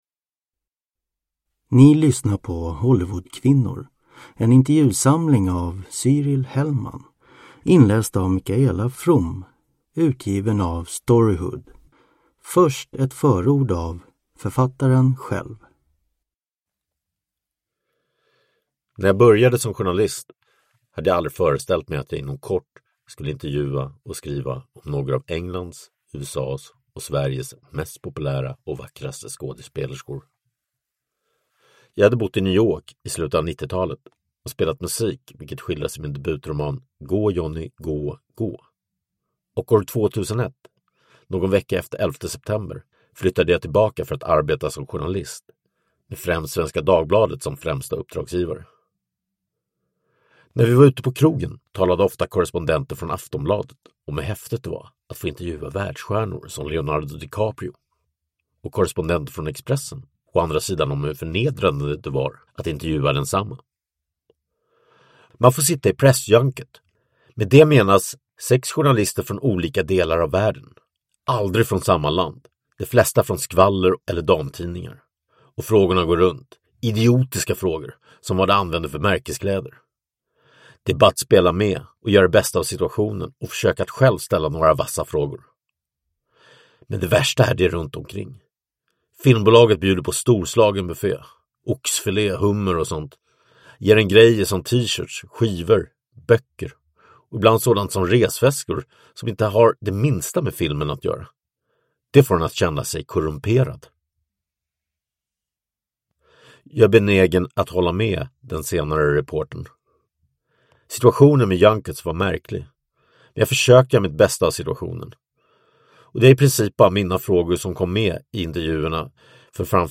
Hollywoodkvinnor – Ljudbok – Laddas ner